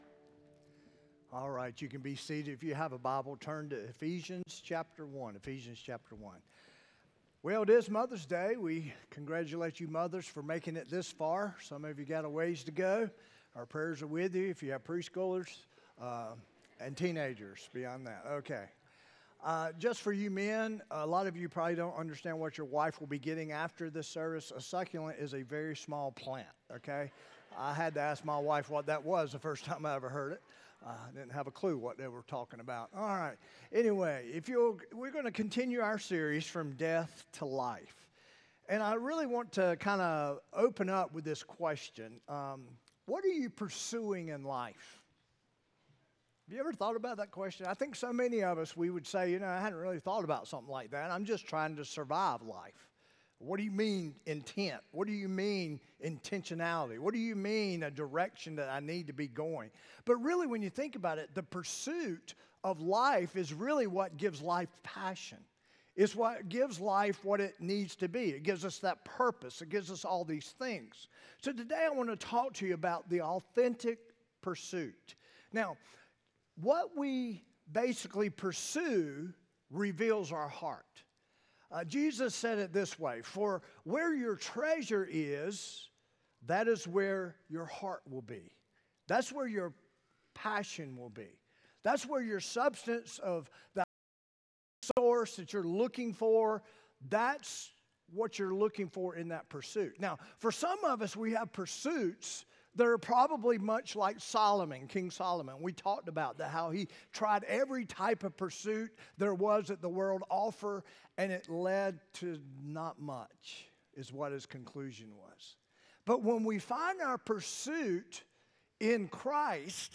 5-11-25-sermon-audio.m4a